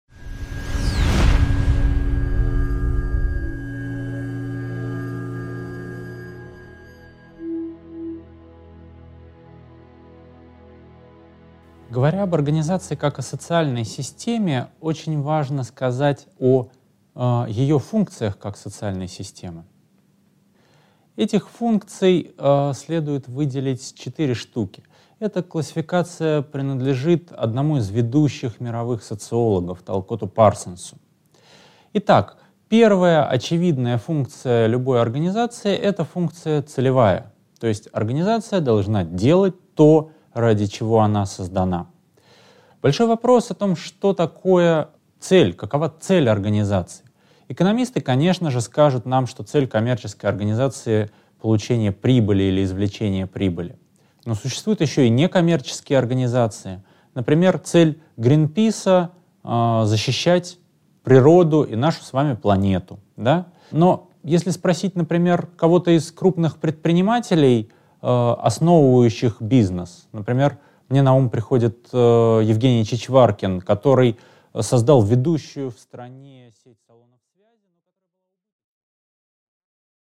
Аудиокнига 1.4. Функции организации как социальной системы | Библиотека аудиокниг